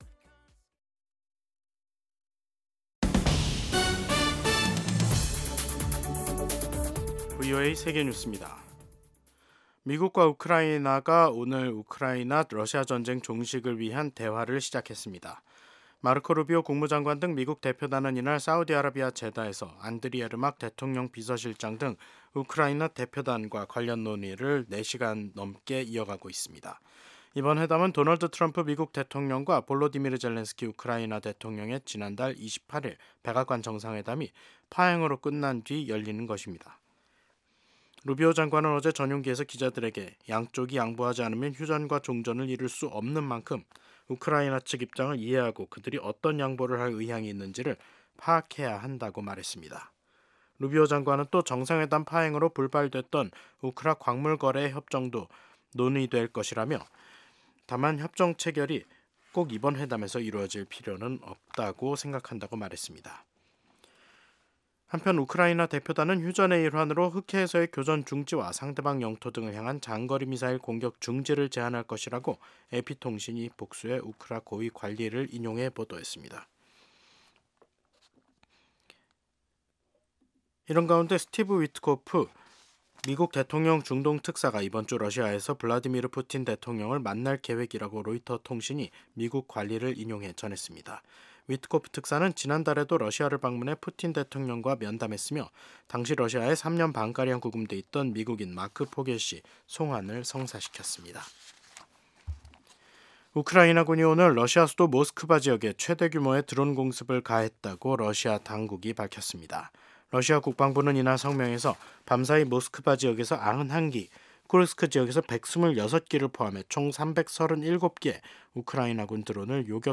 VOA 한국어 간판 뉴스 프로그램 '뉴스 투데이', 2025년 3월 11일 3부 방송입니다. 미국 정부가 도널드 트럼프 대통령 취임 후 이뤄진 북한의 첫 탄도미사일 발사와 관련해 북한의 완전한 비핵화 원칙을 강조했습니다. 미북 간 대화에서 한국이 배제되는 일은 절대 없을 것이라고 주한 미국 대사대리가 밝혔습니다.